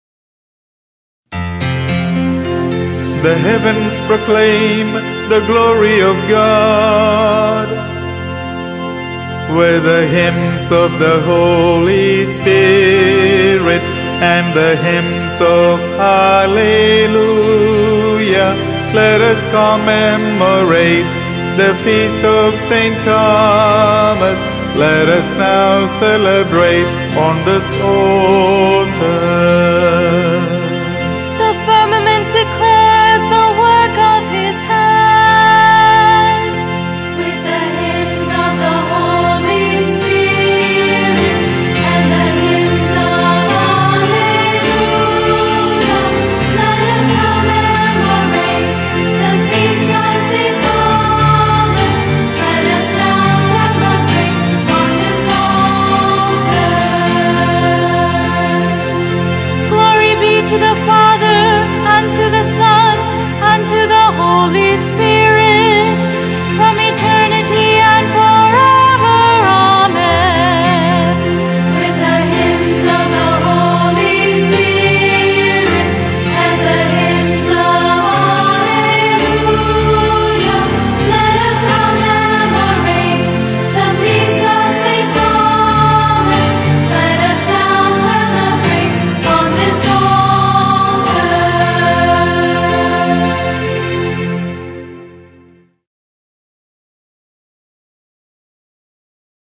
and chorus